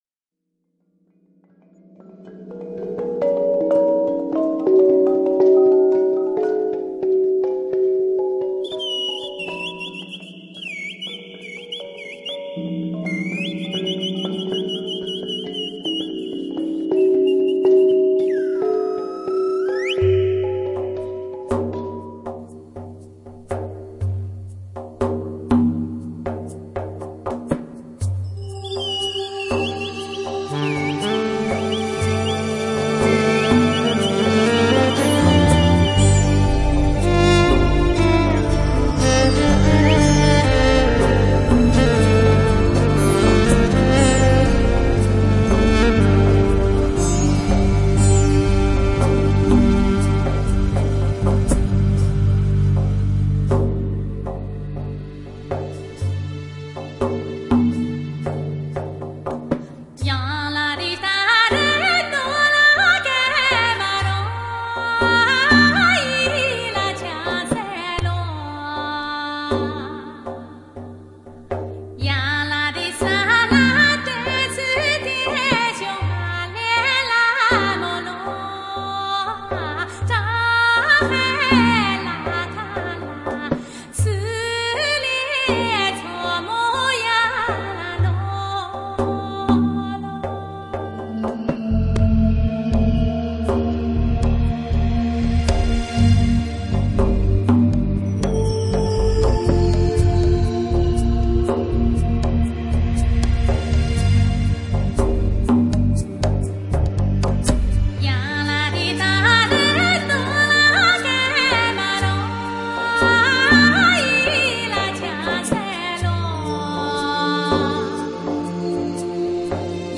淡雅而纯净的音乐，引发人从宁静中轻轻飘落，随着和谐的旋律，产生悠远的遐想和启示。